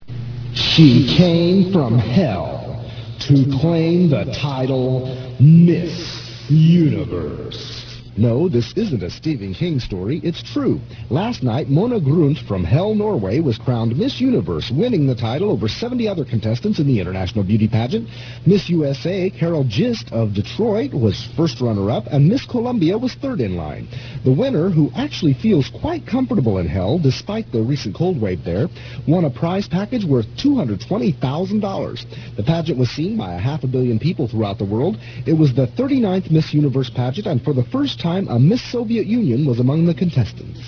I am all voices.
The sound quality is very good considering how much it scrunches the files.
Most of those examples were captured on normal bias analog tape.